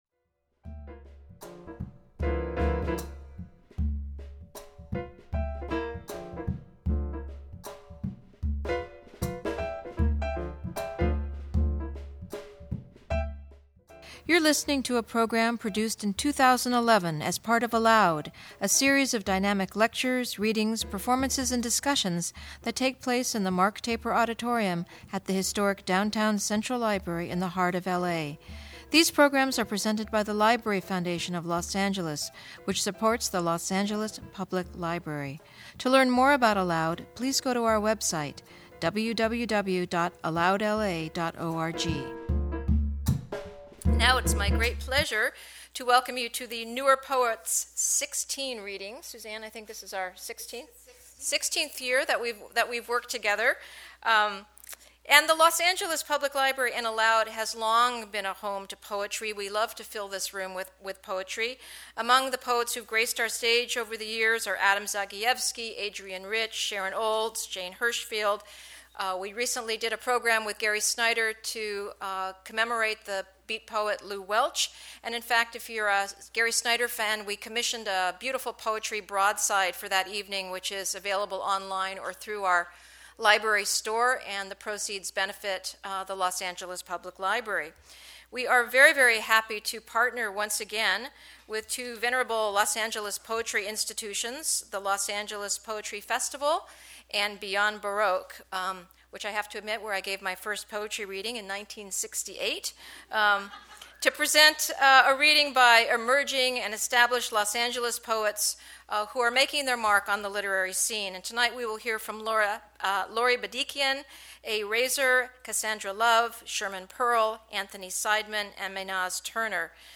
email Thursday, July 7, 2011 01:15:37 ALOUD Listen: play pause stop / 486.mp3 Listen Download this episode Episode Summary In this popular, long-running event, six talented Los Angeles poets present short readings of their work.